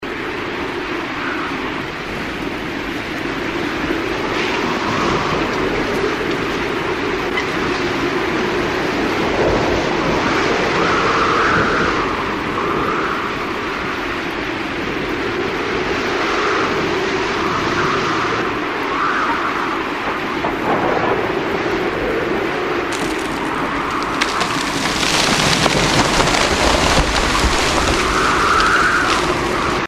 Звуки деревьев